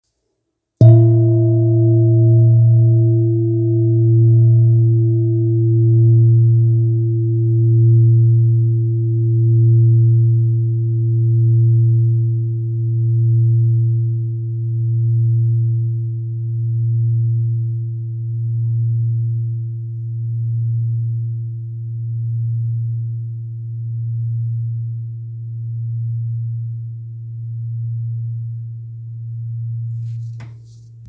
Kopre Singing Bowl, Buddhist Hand Beaten, Antique Finishing, Select Accessories
Material Seven Bronze Metal
It is accessible both in high tone and low tone .
In any case, it is likewise famous for enduring sounds. Kopre Antique Singing Bowls is accessible in seven different chakras tone.